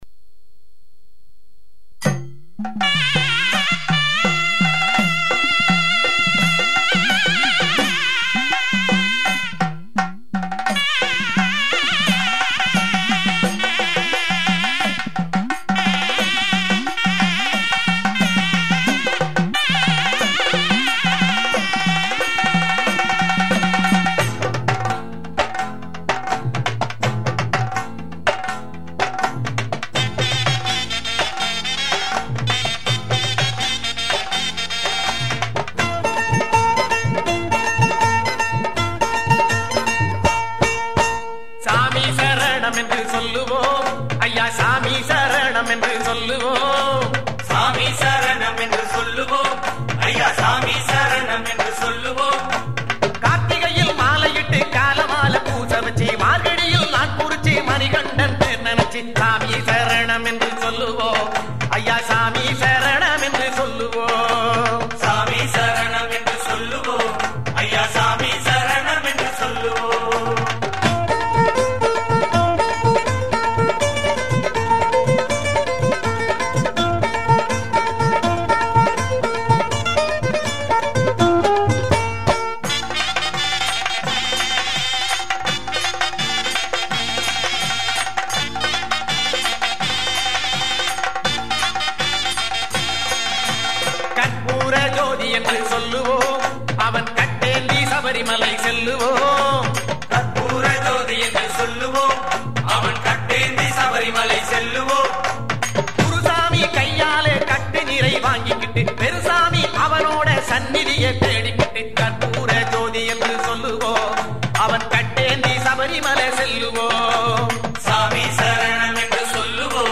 Tamil devotional album